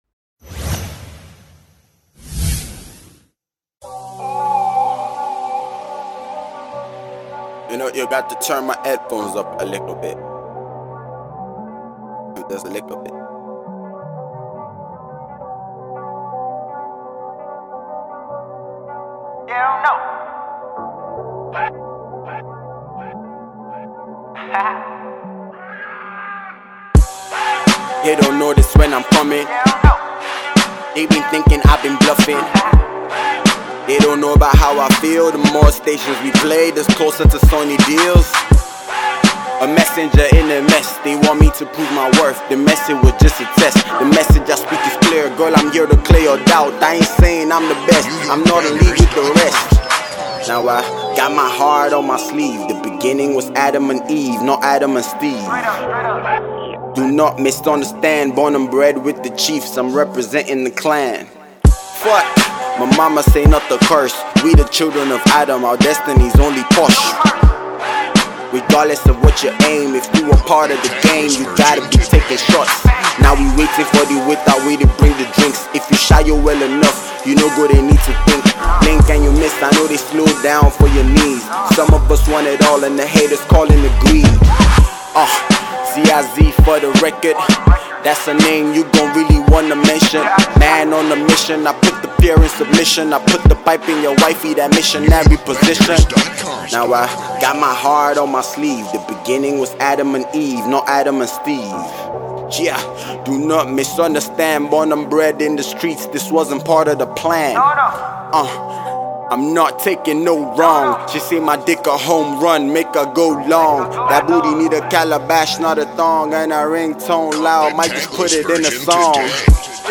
Nigerian rapper
delivering bars after bars on the wavy cut.